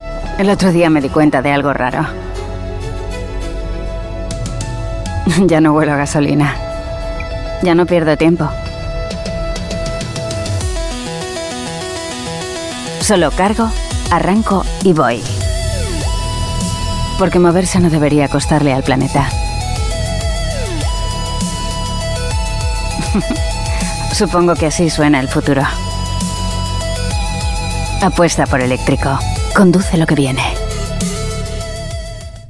Kastilisch-spanischer Online-Sprecher, der fließend Englisch spricht.
Qualität: Professionelles Studio mit erstklassiger Tonqualität zum Aufzeichnen von TV-Werbespots, Lippensynchronisation und jeglicher Form multimedialer Unternehmensarbeit.
Schalldichte Aufnahmekabine (Studiobricks)
Mikrofon Neumann U87 Ai